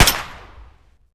wpn_pistol10mm_fire_2d.wav